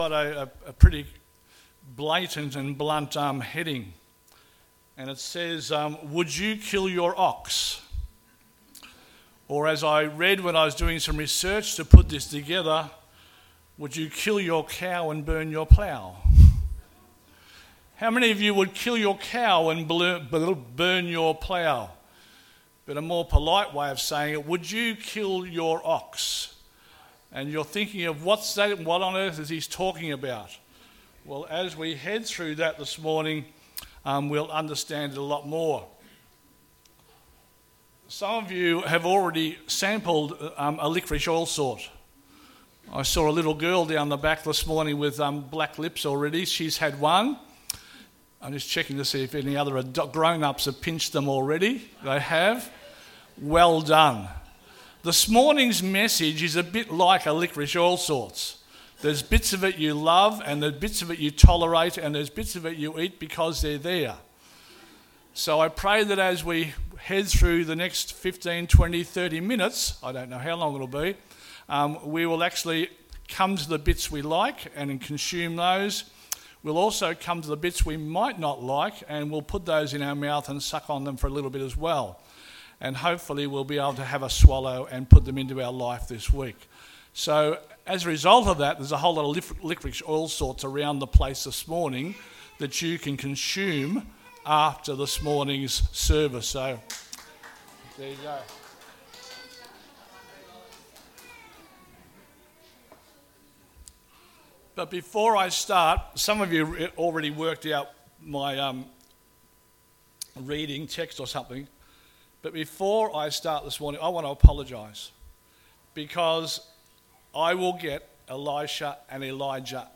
2026 • 23.66 MB Listen to Sermon Download this Sermon Download this Sermon To download this sermon